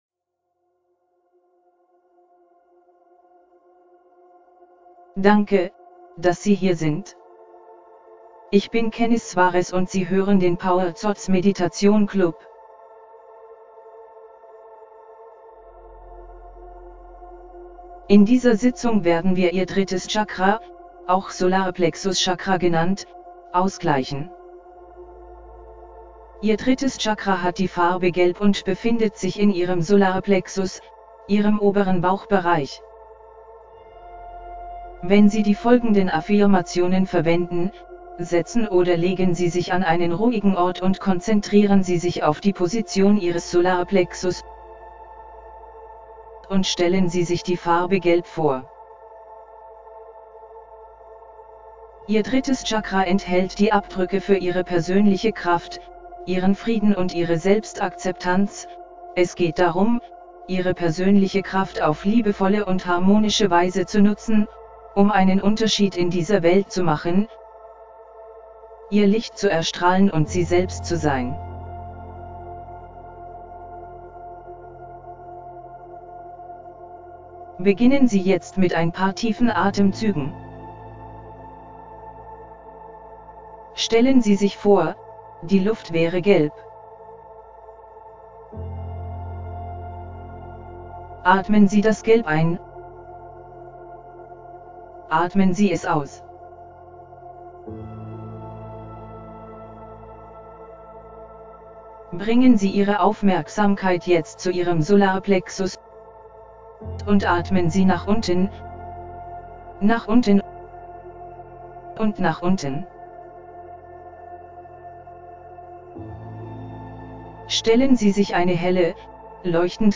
3ActivatingQiFlowOfSolarPlexusChakraMeditationDE.mp3